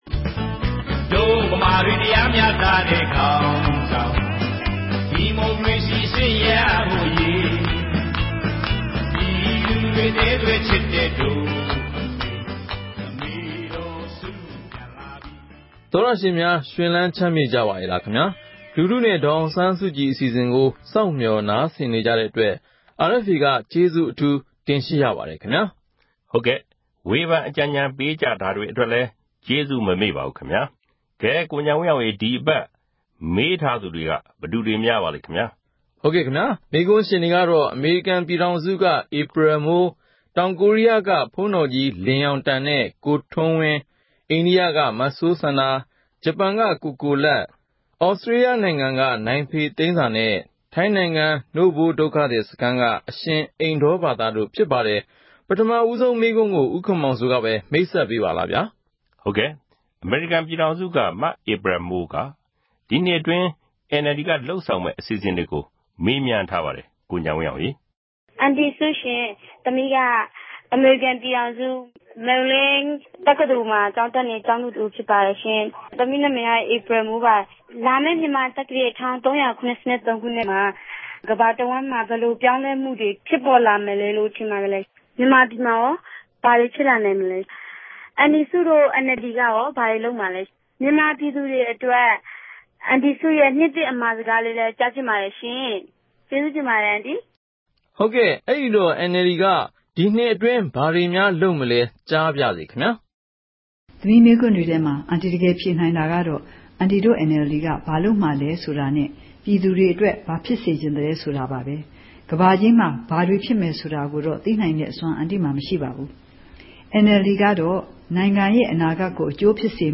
လူထုနဲ့ ဒေါ်အောင်ဆန်းစုကြည် အစီအစဉ်ကို RFA က အပတ်စဉ် သောကြာနေ့ ညတိုင်းနဲ့ ဗုဒ္ဓဟူးနေ့ မနက်တိုင်း တင်ဆက်နေပါတယ်။ ဒီ အစီအစဉ်ကနေ ပြည်သူတွေ သိချင်တဲ့ မေးခွန်းတွေကို ဒေါ်အောင်ဆန်းစုကြည် ကိုယ်တိုင် ဖြေကြားပေးမှာ ဖြစ်ပါတယ်။